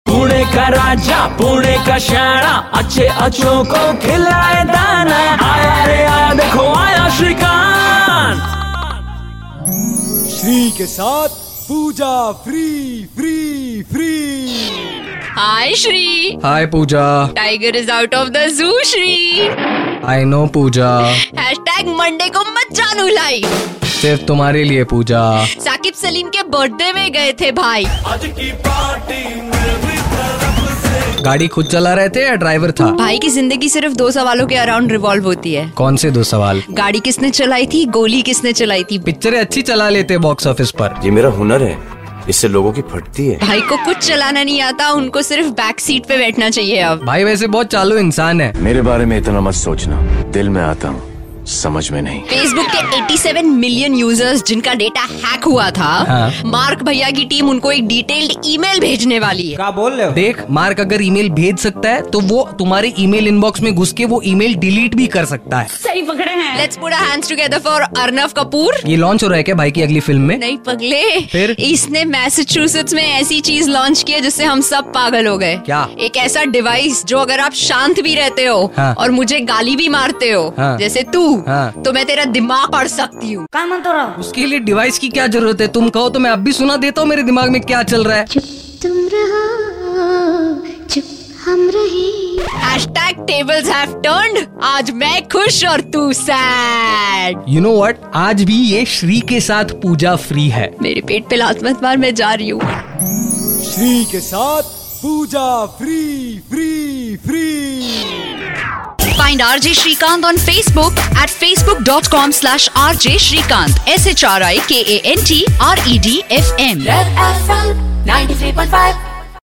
CLICK TO LISTEN TO THEIR HILARIOUS CONVERSATION